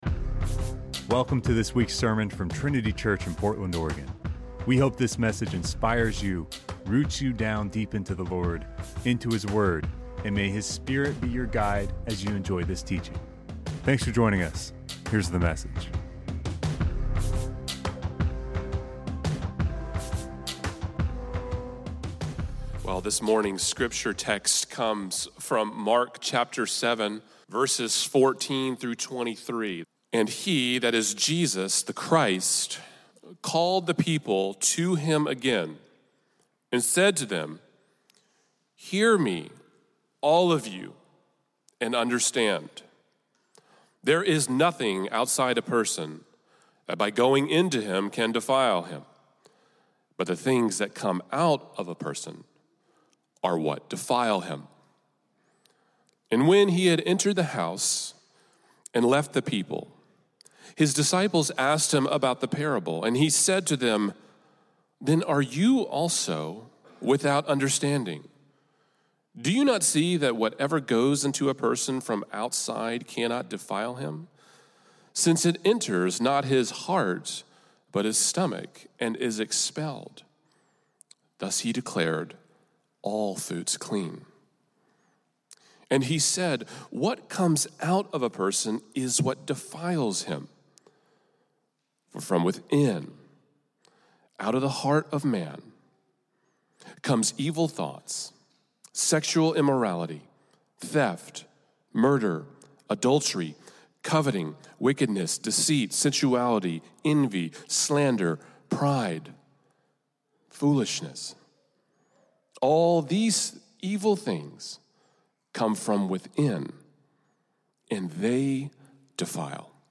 Welcome to this week’s sermon from Trinity Church in Portland, Oregon.